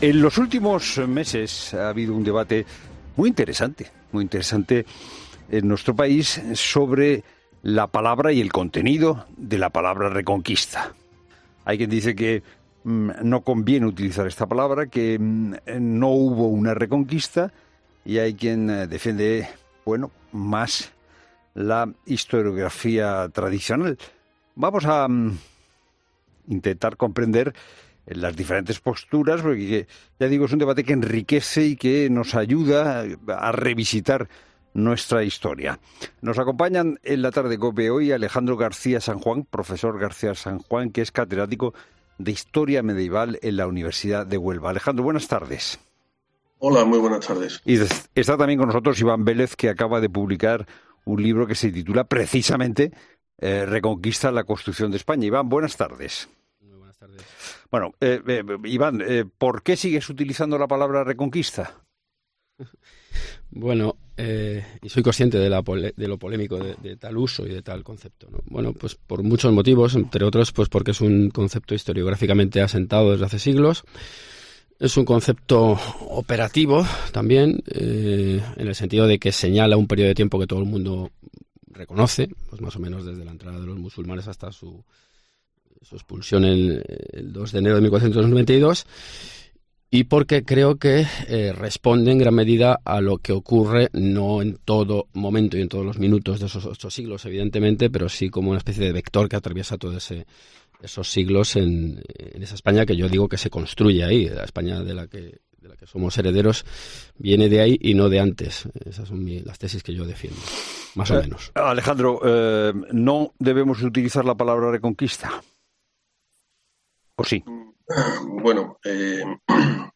Dos expertos en Historia debaten en La Tarde si la conquista de los territorios musulmanes supuso realmente el germen de la nación española